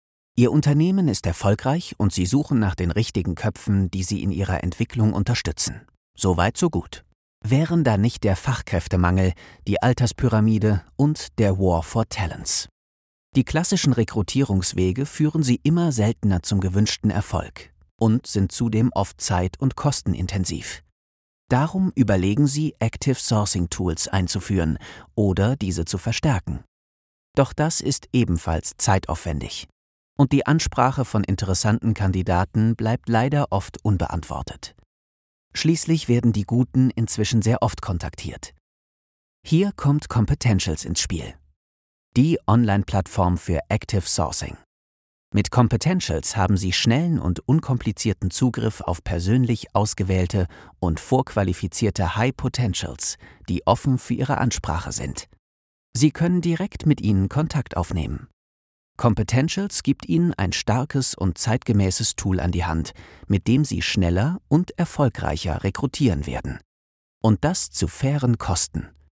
Jung, dynamisch, cool & souverän!
Sprechprobe: eLearning (Muttersprache):
young german voice over artist